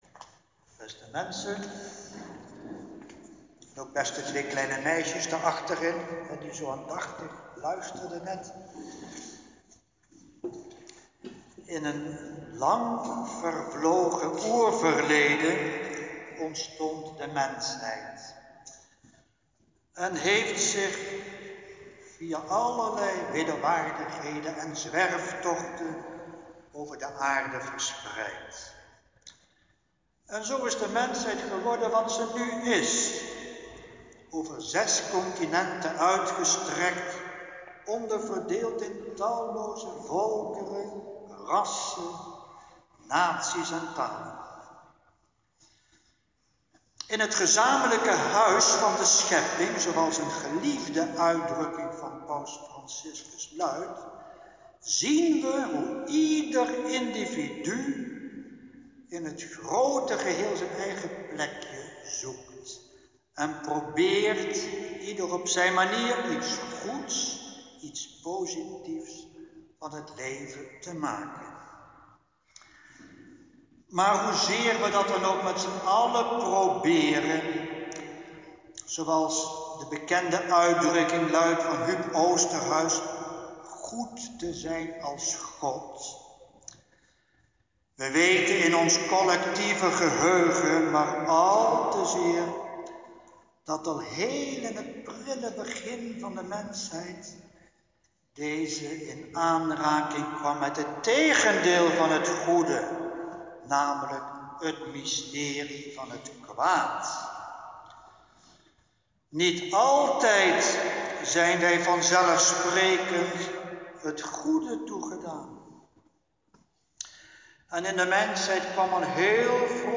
Inleidend woord, preek en slotwoord